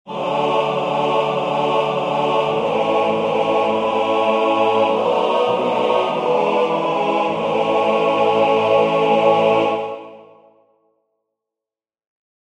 Key written in: A Major
How many parts: 4
Type: Barbershop
All Parts mix: